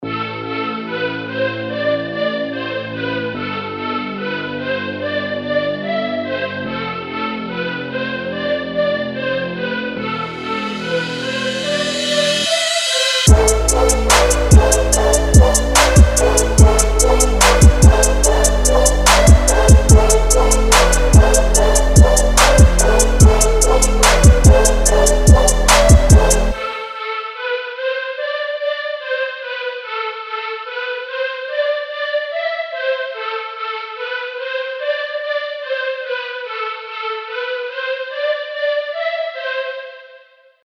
预期重的808音调，脆脆的军鼓和掌声，猛烈的踢鼓，快速的踩hat和旋律会从一开始就一直持续出现在听众的耳朵中。